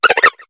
Cri de Flamajou dans Pokémon HOME.